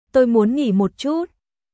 Tôi muốn nghỉ một chút.少し休憩したいですトイ ムオン ンギー モッ チュット